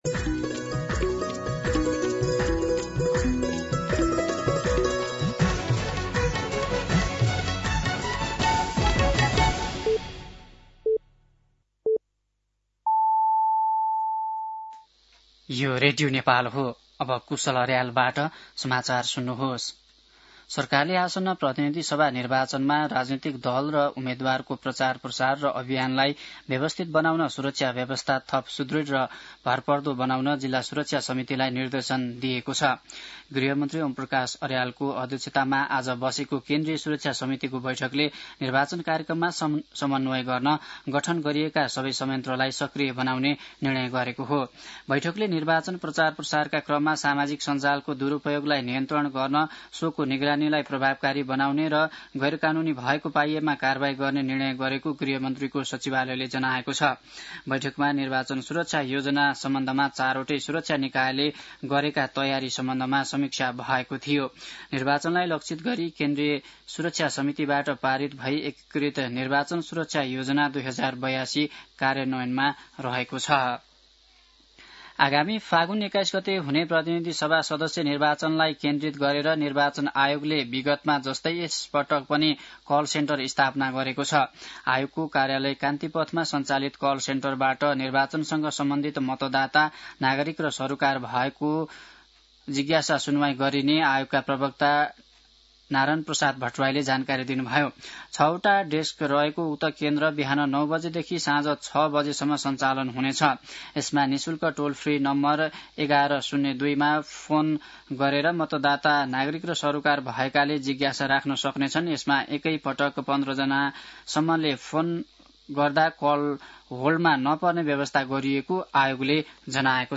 दिउँसो ४ बजेको नेपाली समाचार : ७ माघ , २०८२
4-pm-Nepali-News-6.mp3